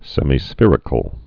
(sĕmē-sfîrĭ-kəl, -sfĕr-, sĕmī-)